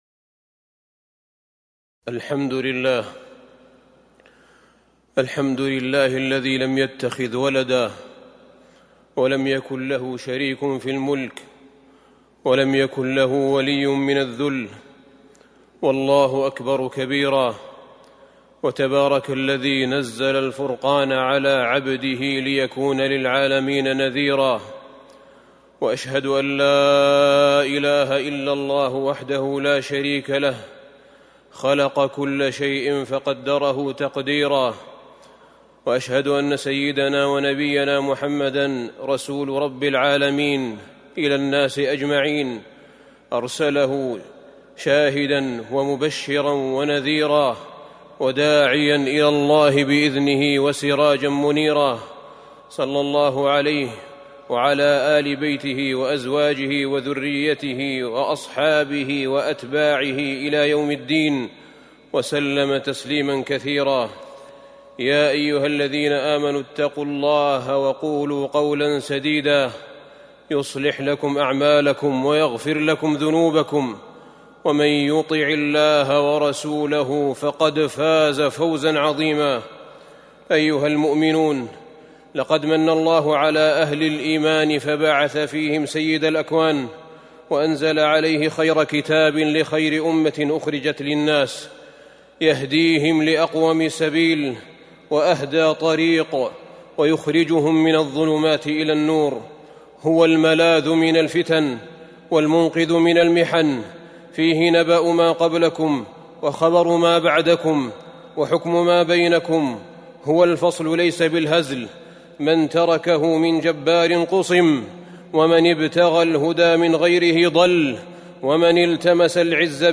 تاريخ النشر ٢٣ ربيع الأول ١٤٤٣ هـ المكان: المسجد النبوي الشيخ: فضيلة الشيخ أحمد بن طالب بن حميد فضيلة الشيخ أحمد بن طالب بن حميد قد جاءكم من الله نورٌ وكتابٌ مبين